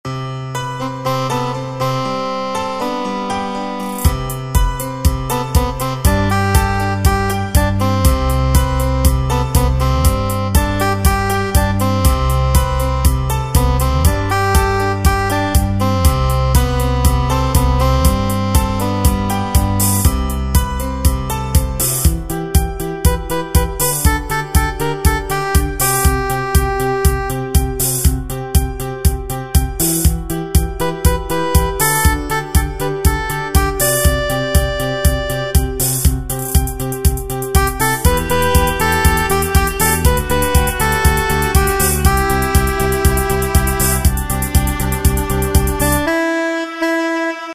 Tempo: 120 BPM.
MP3 with melody DEMO 30s (0.5 MB)zdarma